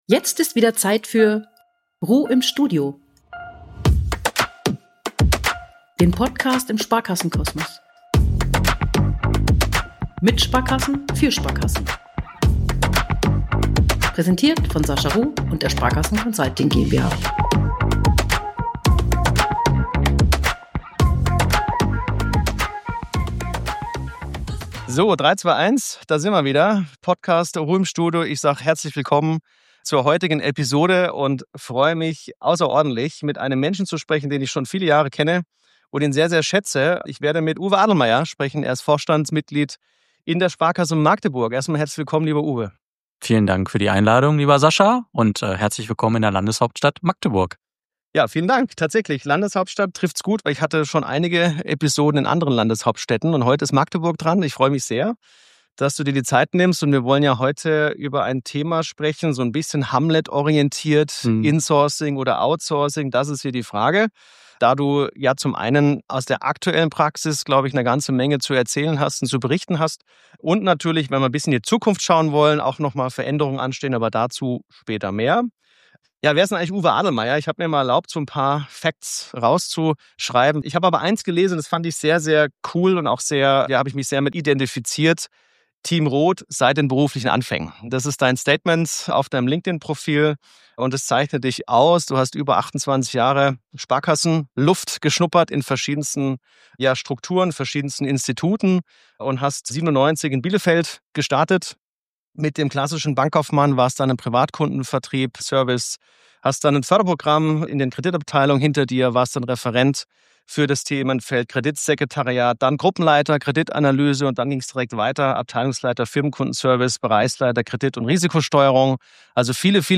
Ein spannendes Gespräch über strategische Klarheit, partnerschaftliche Zusammenarbeit – und die Notwendigkeit, Dinge rechtzeitig und konsequent anzupacken.